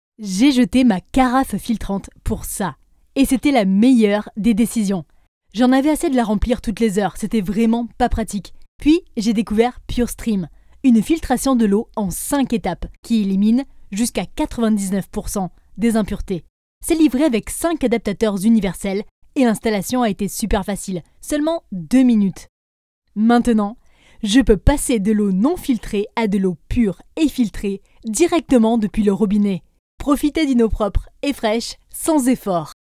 I’m a native French voice-over artist and producer with years of experience delivering high-quality voice work.
Gear: I use Ableton Live 11, a MOTU UltraLite interface, and a Blue Baby Bottle microphone for professional-grade sound.
Sprechprobe: Werbung (Muttersprache):